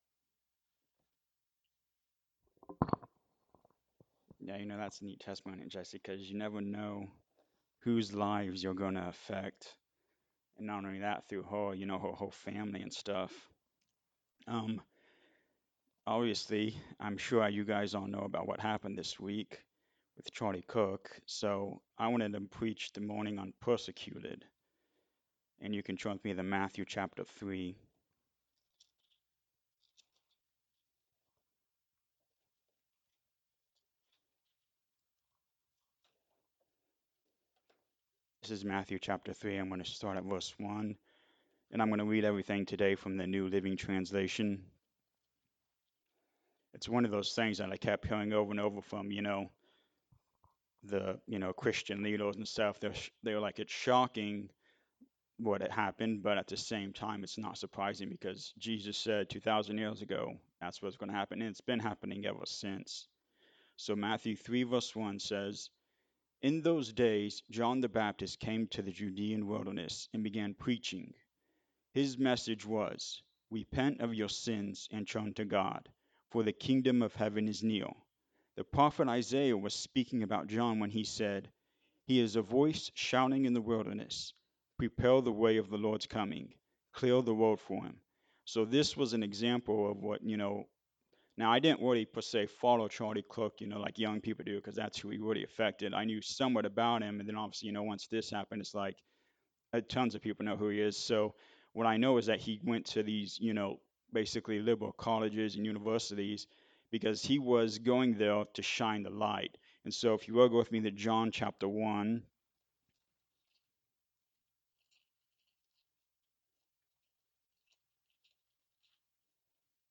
Matthew 3:1-3 Service Type: Sunday Morning Service Be a light.